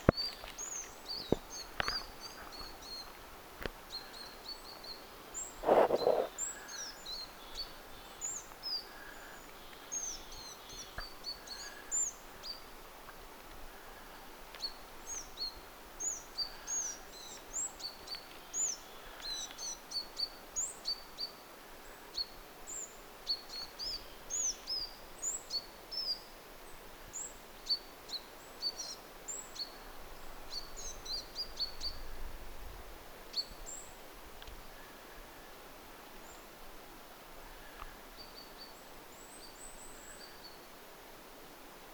nuori töyhtötiainen
sellaiseksi bizt-tyyliseksi,
on aika lähellä vit-tiltaltin ääntä?
ilm_nuori_toyhtotiainen_aantelya.mp3